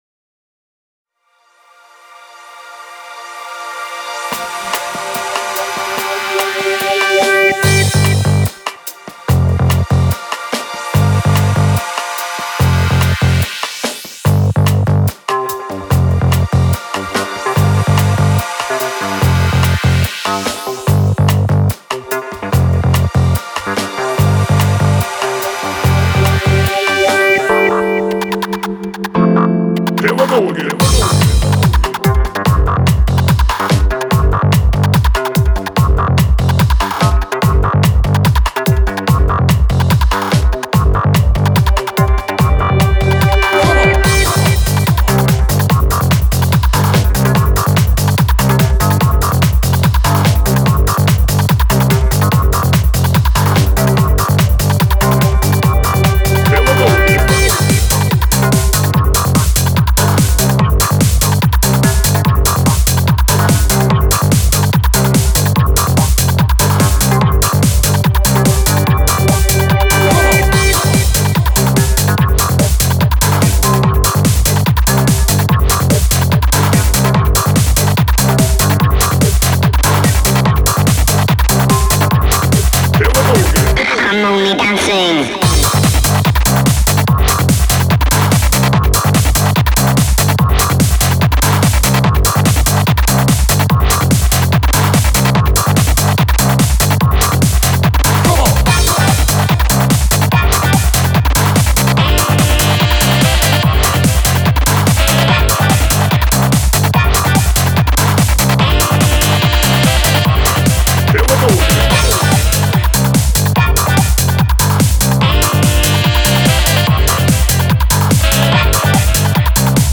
Файл в обменнике2 Myзыкa->Psy-trance, Full-on
Style: Psy-Trance, Suomi
Quality: VBR V0 / Joint Stereo